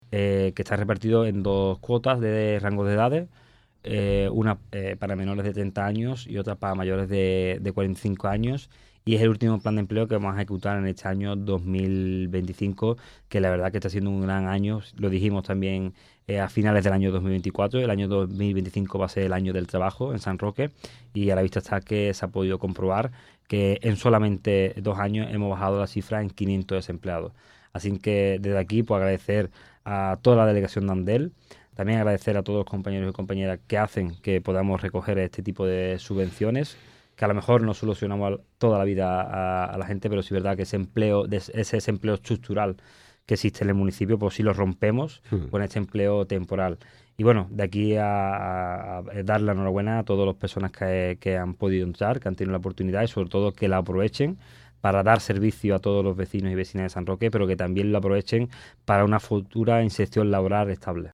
El concejal de Universidad Popular y Empleo, Fernando Vega, ha destacado en su sección en directo en Canal San Roque RTVM la buena salud de Amdel, con varios planes de empleo en marcha para diferentes sectores de edad, así como de la Universidad Popular, donde hizo un magnifico balance del curso pasado que supuso un récord histórico de matriculaciones.
TOTAL_FERNANDO_VEGA_RADIO.mp3